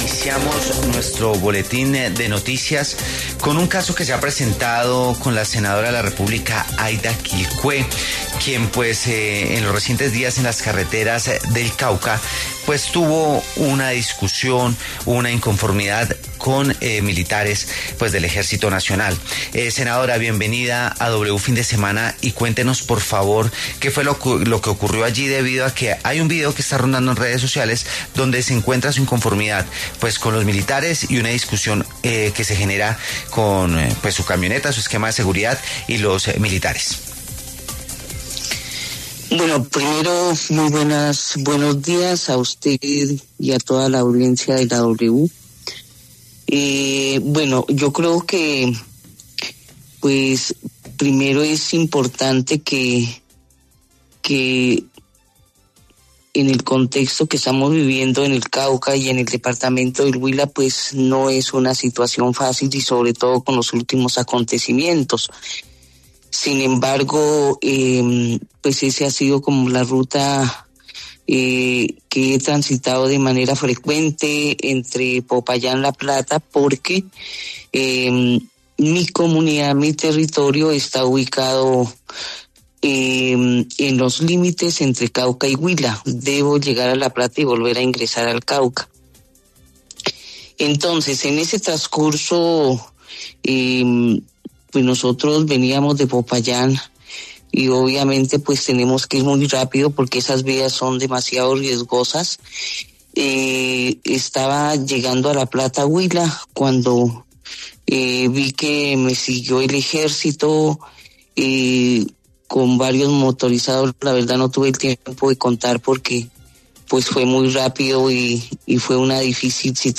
En diálogo con W Fin de Semana, la senadora Aida Quilcué habló sobre la polémica discusión que sostuvo con un contingente del Ejército Nacional en carreteras del Cauca.